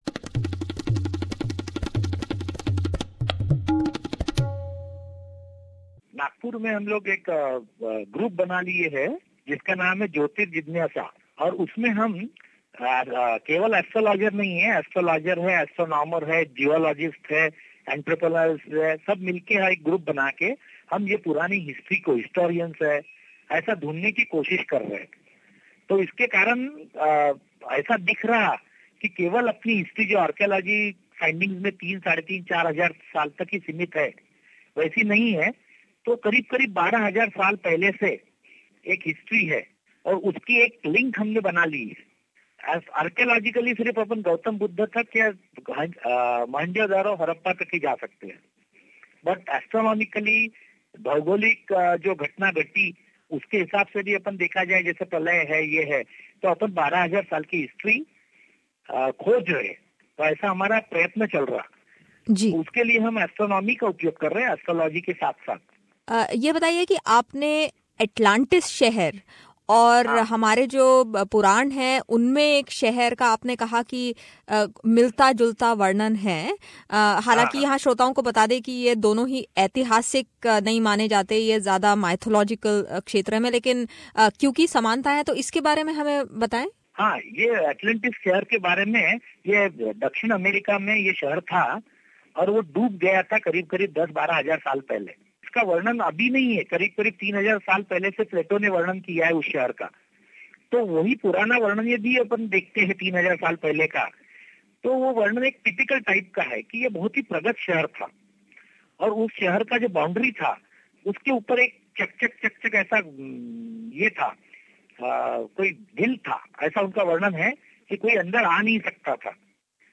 इस विशेष भेंटवार्ता.